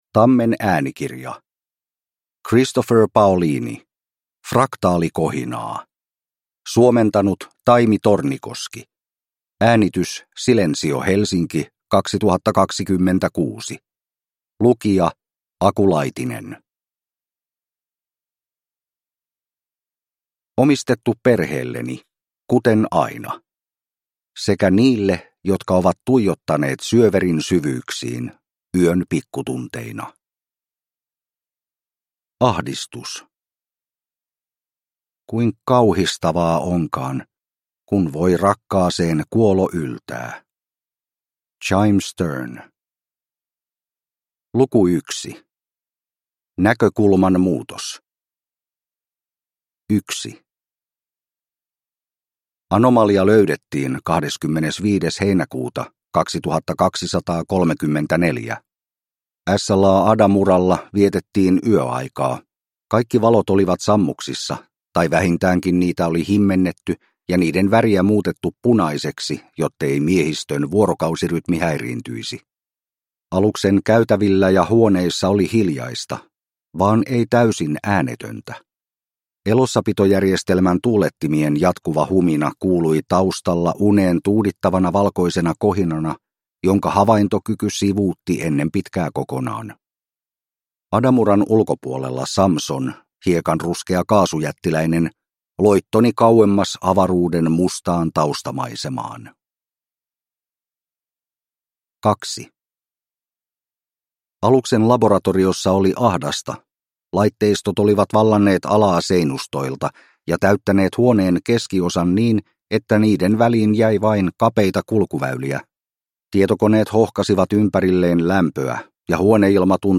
Fraktaalikohinaa – Ljudbok